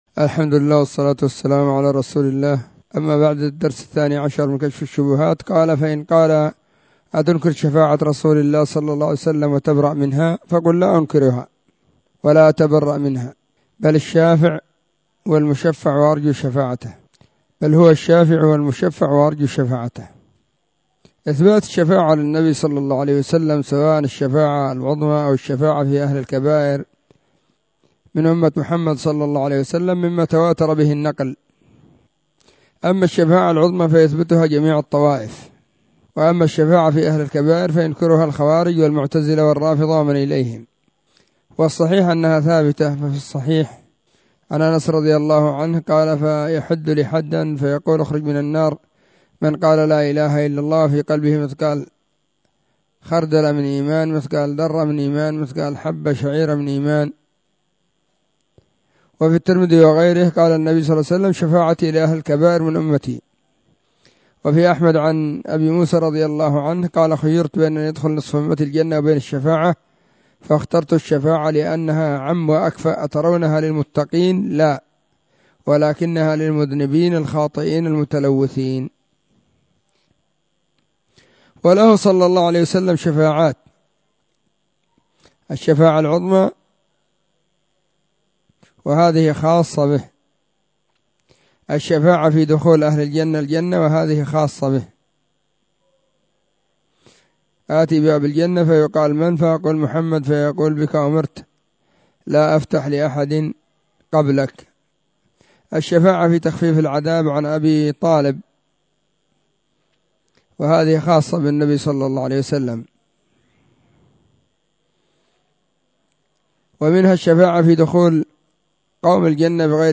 كتاب كشف الشبهات الدرس12
📢 مسجد الصحابة بالغيضة, المهرة، اليمن حرسها الله.